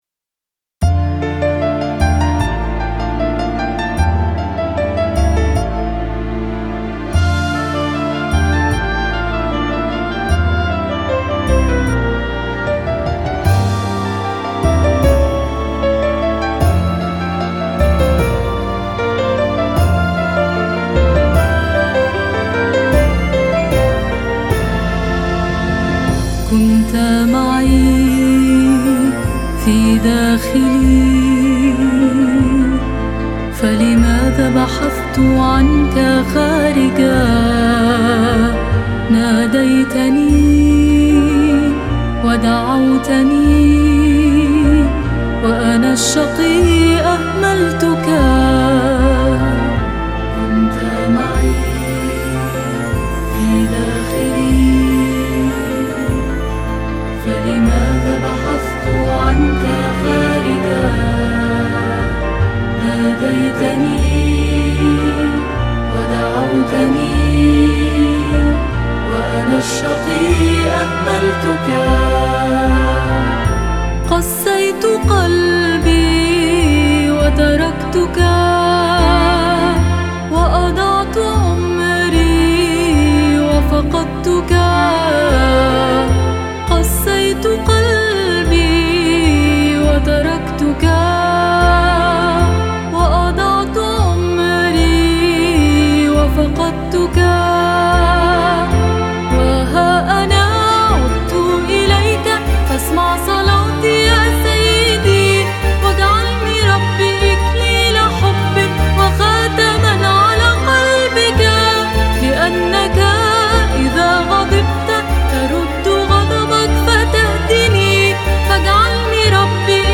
كمان
عود
جيتار